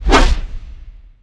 gnoll_commander_swish.wav